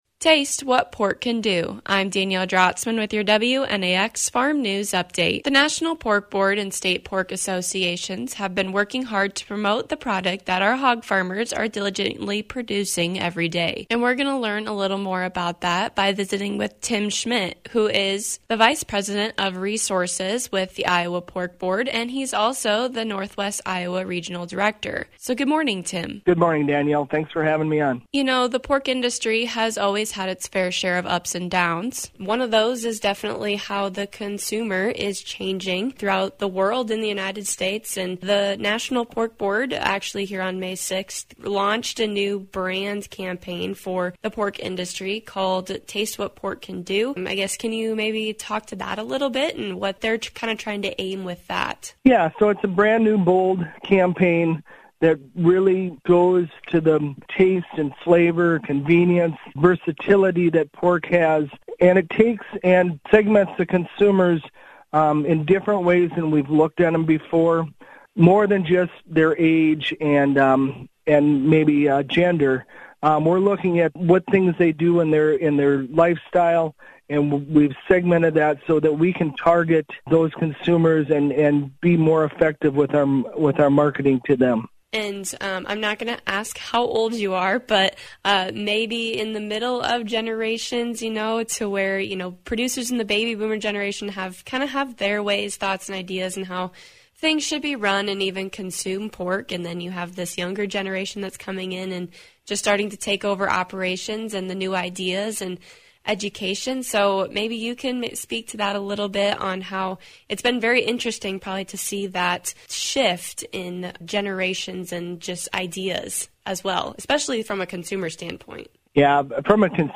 Hear from Iowa pork producer